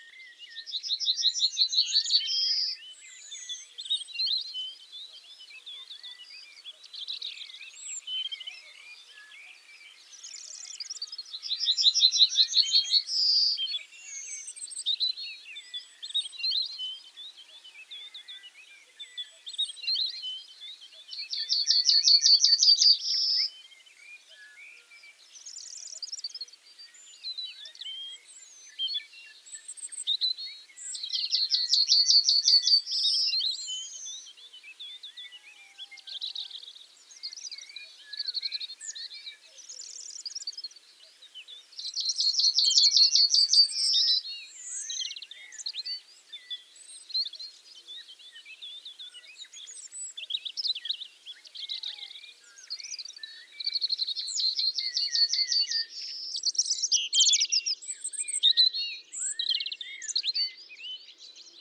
На этой странице собраны звуки пения овсянки — красивые трели и щебетание одной из самых мелодичных птиц.
овсянка поет